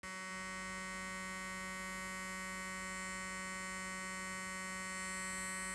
onkyo-sound-without-input.wav